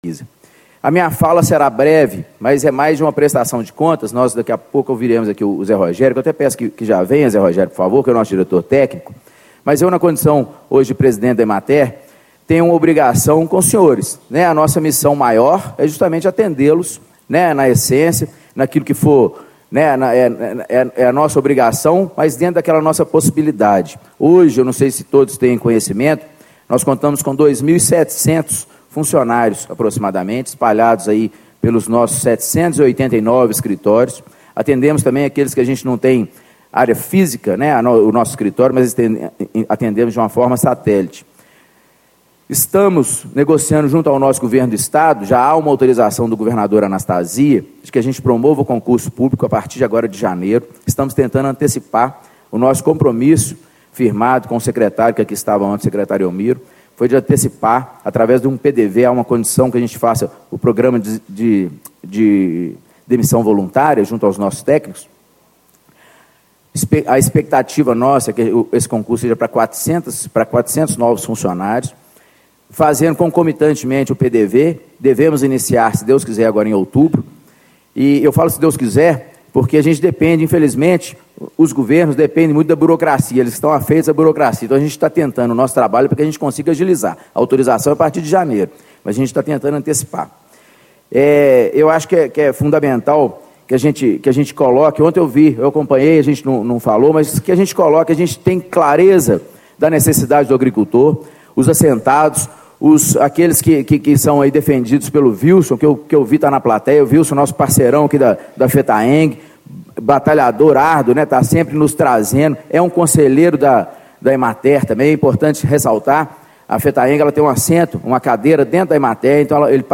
Marcelo Lana Franco, Presidente da Empresa de Assistência Técnica e Extensão Rural do Estado de Minas Gerais - Emater. Painel: Assistência Técnica e Extensão Rural - Ater e Pesquisa
Discursos e Palestras